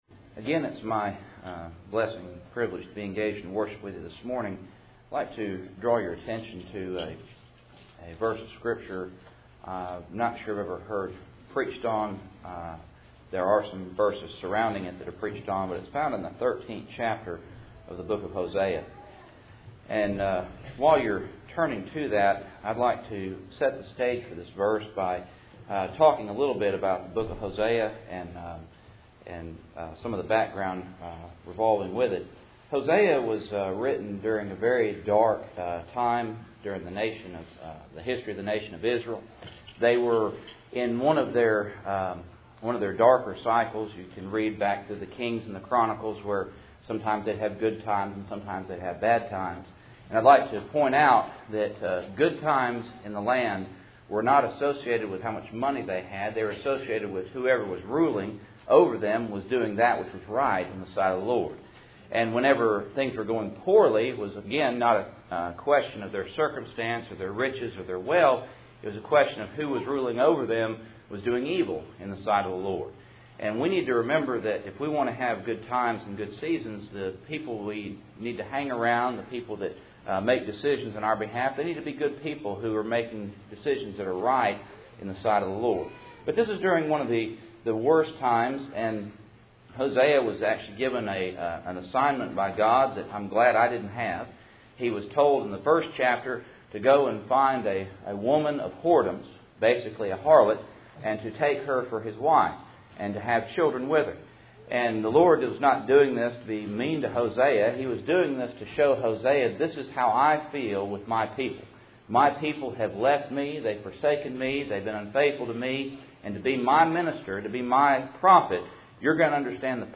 Hosea 13:14 Service Type: Cool Springs PBC 1st Saturday %todo_render% « Stand